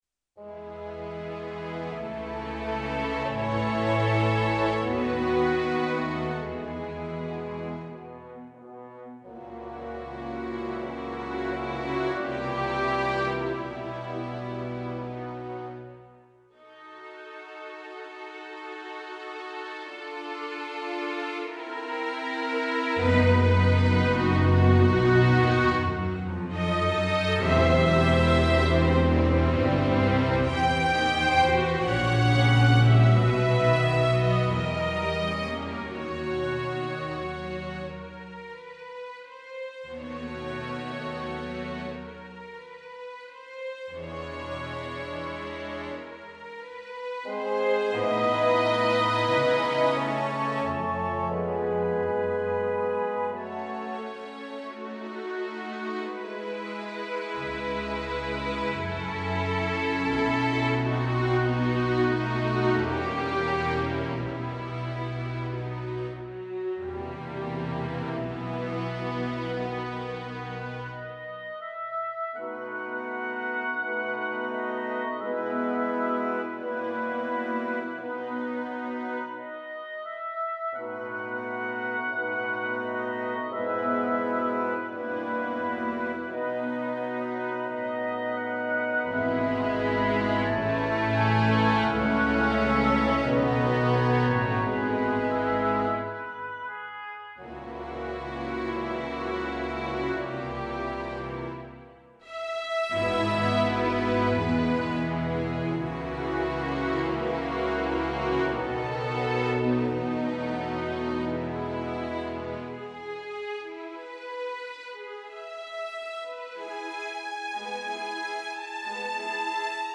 ブラームス：交響曲第1番 ハ短調 Op.68 第2楽章 [2023]
Andante sostenuto [9:14]
Brahms-Symphony-No1-2nd-2023.mp3